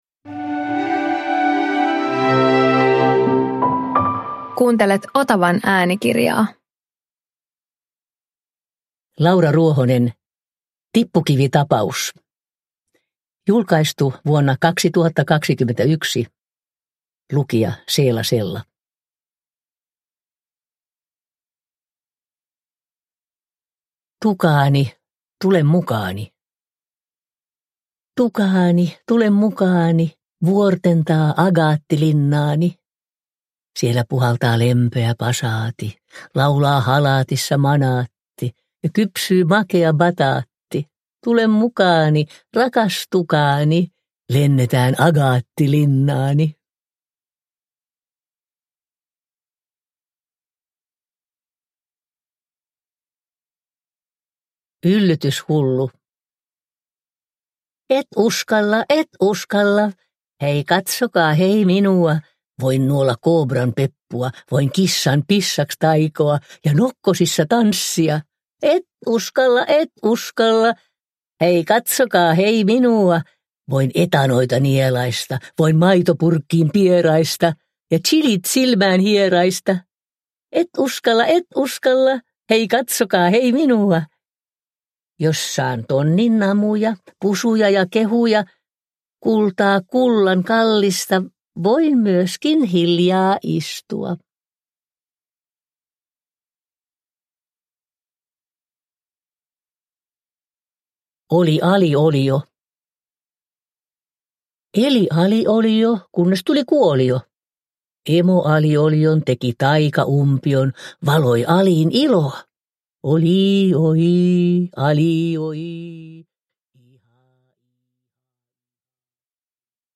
Tippukivitapaus – Ljudbok – Laddas ner
Lastenrunotapaus!
Uppläsare: Seela Sella